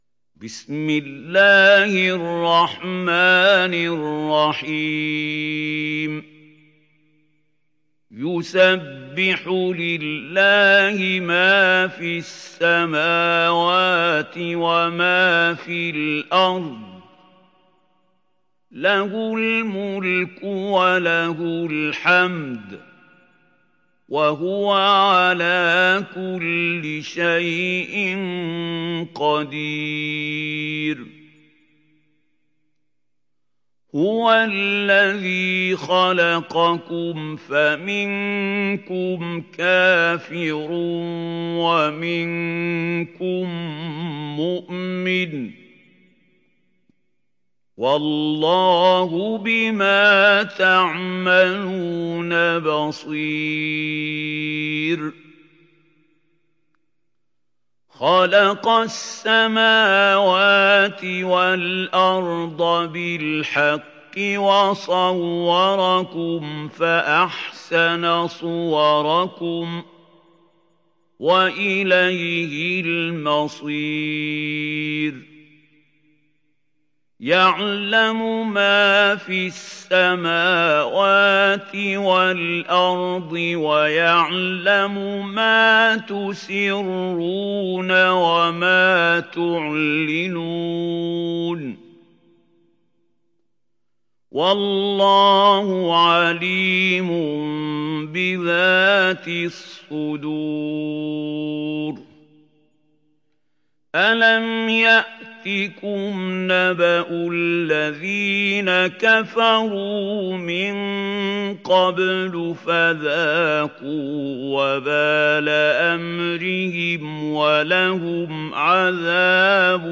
Tegabun Suresi İndir mp3 Mahmoud Khalil Al Hussary Riwayat Hafs an Asim, Kurani indirin ve mp3 tam doğrudan bağlantılar dinle